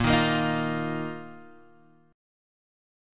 A#sus4 chord